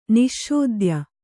♪ niśśodya